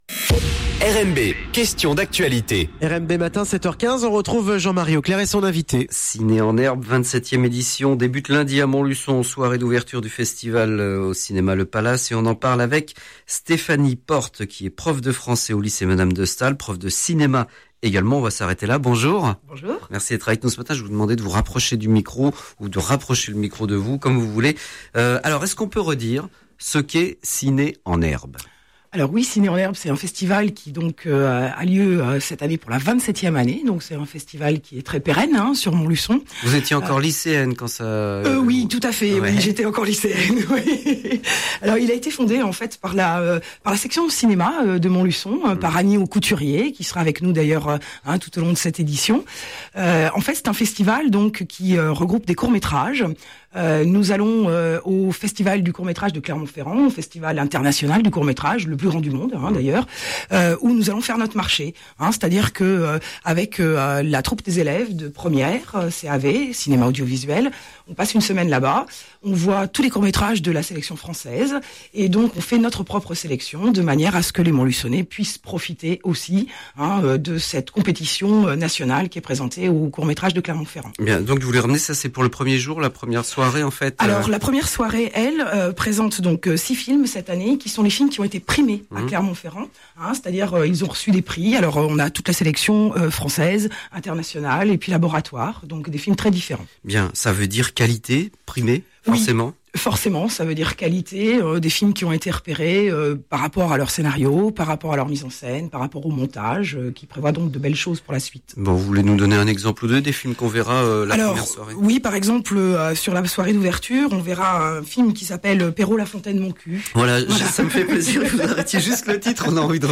L’émission « Invité du jour » diffusé en direct à 7H15 le mercredi 1er avril :